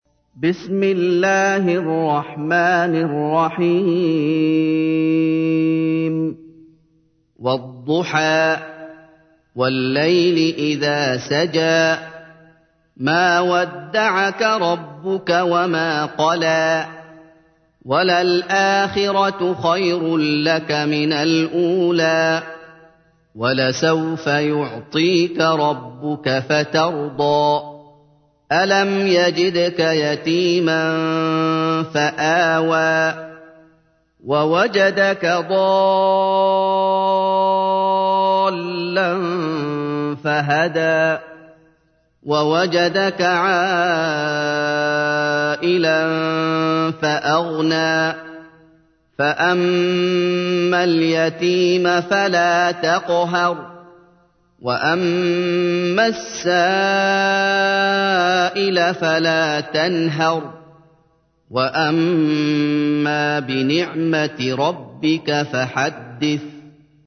تحميل : 93. سورة الضحى / القارئ محمد أيوب / القرآن الكريم / موقع يا حسين